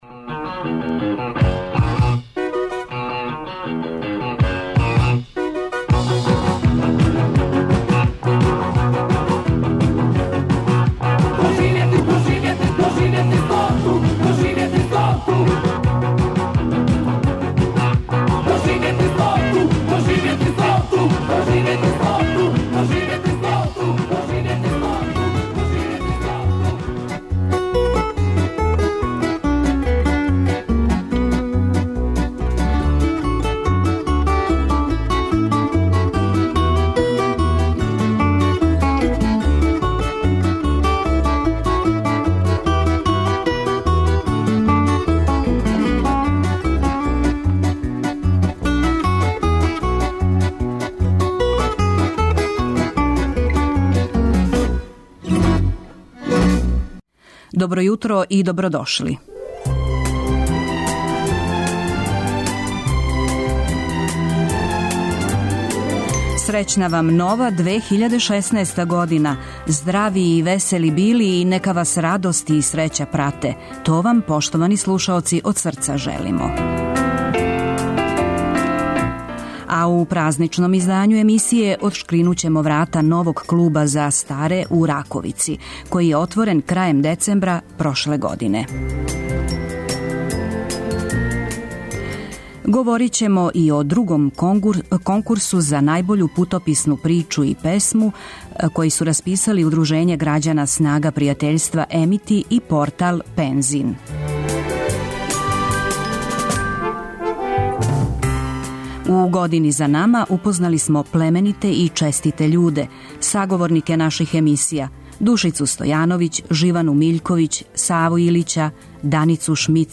У новогодишњем празничном издању емисије одшкринућемо врата новог Клуба за старе у Раковици. Крајем децембра, прошле године, министар за рад и социјалну политику Александар Вулин и градоначелник Београда Синиша Мали отворили су дневни центар за око 500 пензионера у тој општини, а ми ћемо вам пренети део веселе атмосфере са тог скупа.